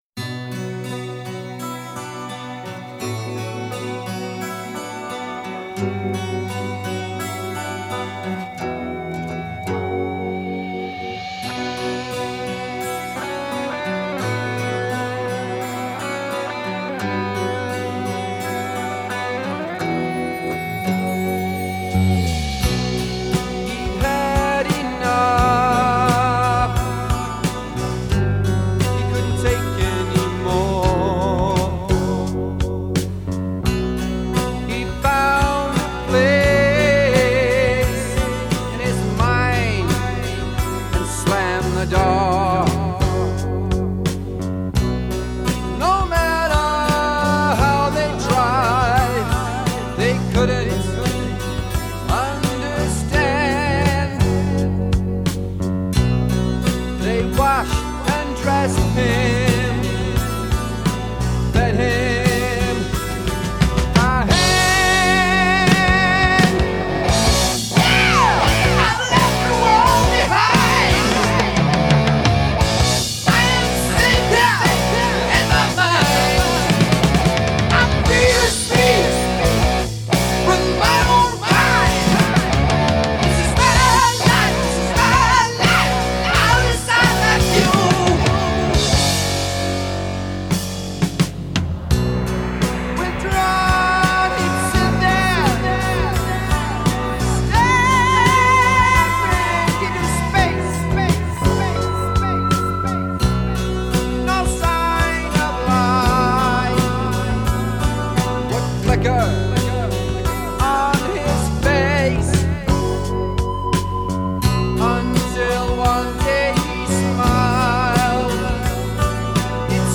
Жанр: Хэви-метал, спид-метал, хард-рок, грув-метал